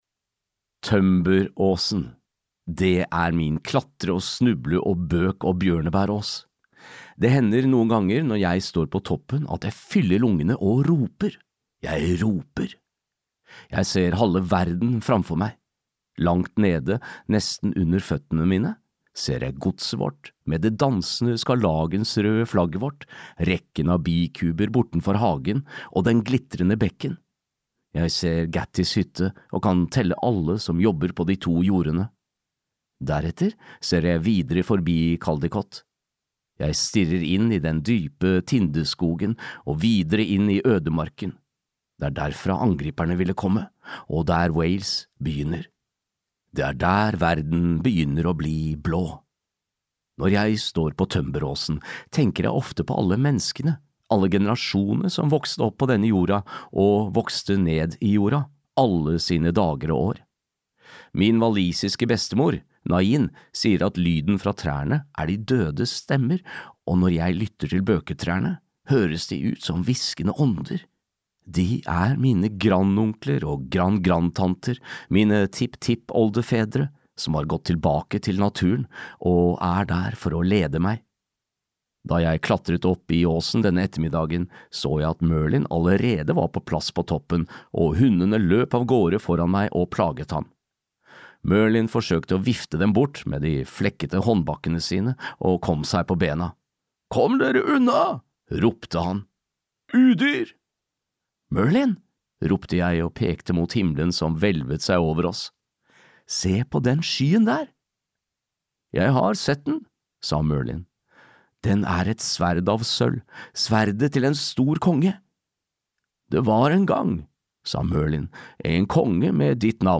Arthur - den seende steinen (lydbok) av Kevin Crossley-Holland